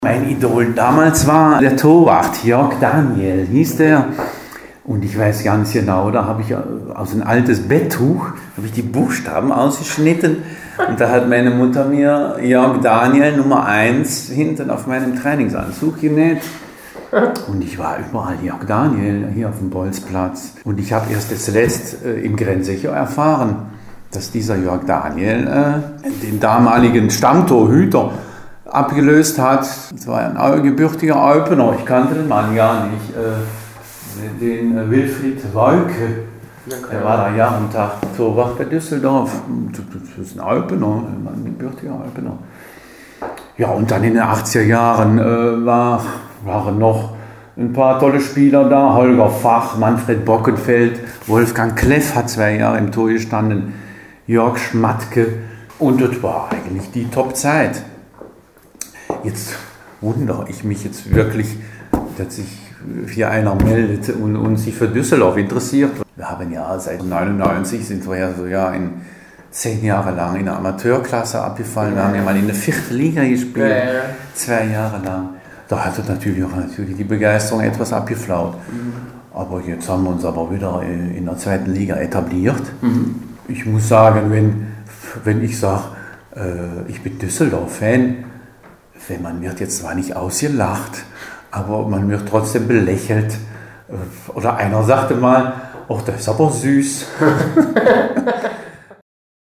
Fußball: Eupener Fortuna-Fan im Interview